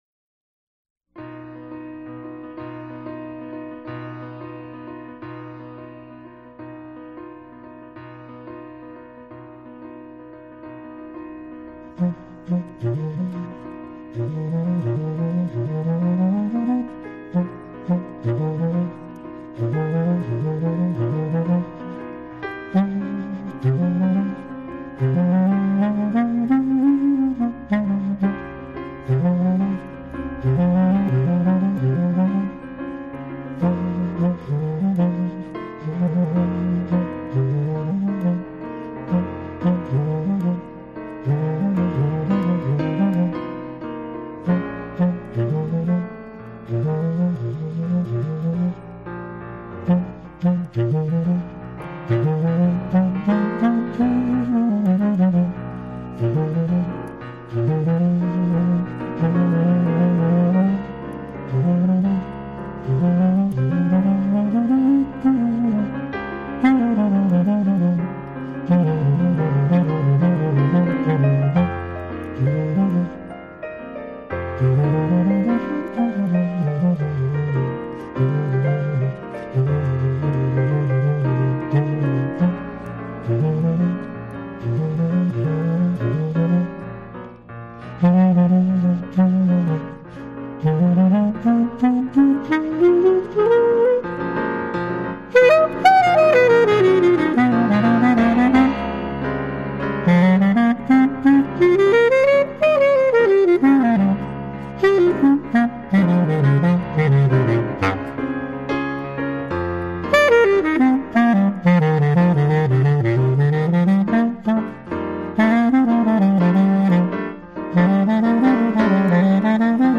alto clarinet
piano
bass
drums